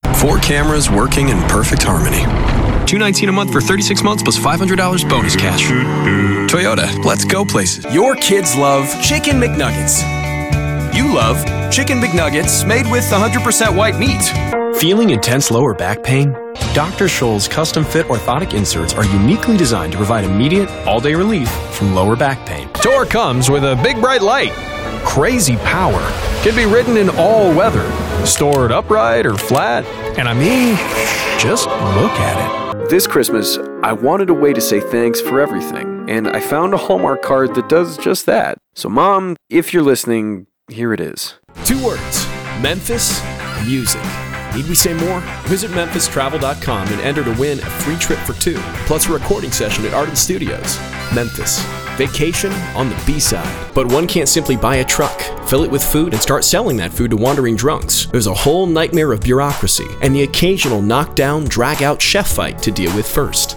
Commercial Showreel
Male
American Standard
Gravelly
Warm